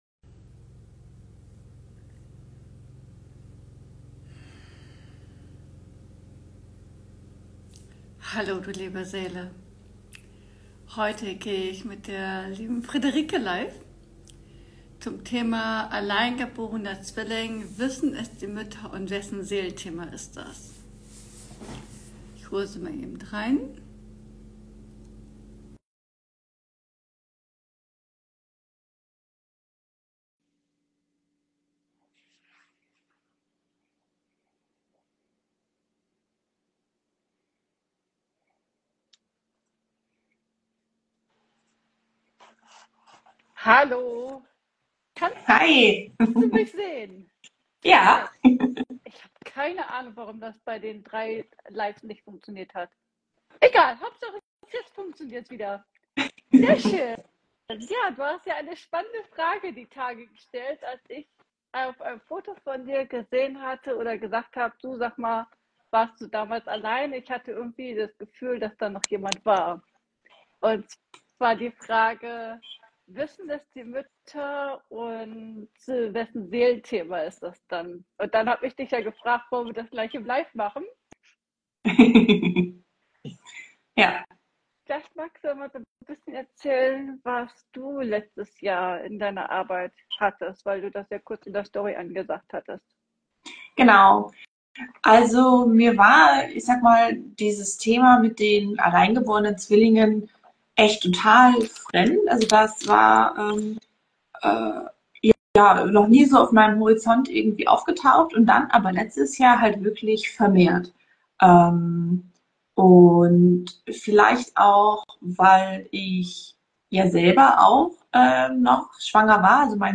In diesem aufschlussreichen Live-Gespräch beleuchten wir das oft unbekannte Thema der alleingeborenen Zwillinge.